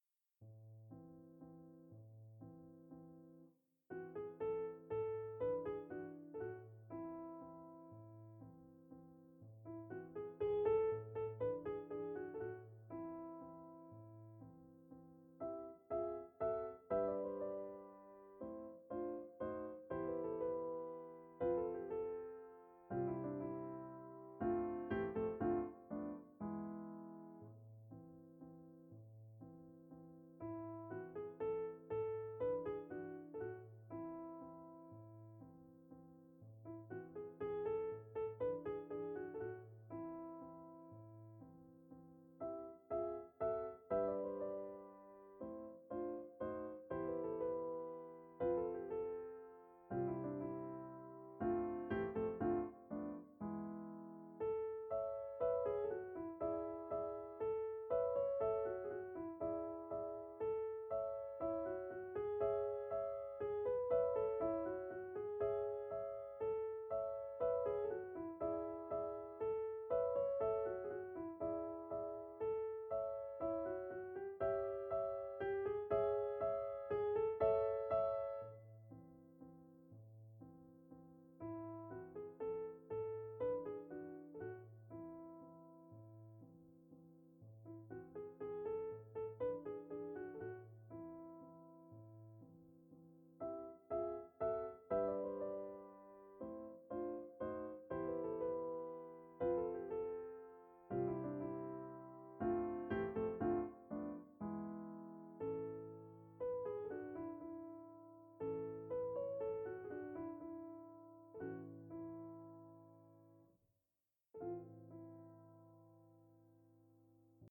Das Eight Eight Ensemble 2.3 – warum heißt es eigentlich Ensemble? – basiert auf einem Steinway CD 327 Konzert Flügel.
Klangbeispiele: 2 mal Klassik und einmal Pop 01 - Sonivox-EightyEight 02 - Sonivox-EightyEight 03 - Sonivox-EightyEight Erster Eindruck: Der Flügel hat einen wirklich guten Klang.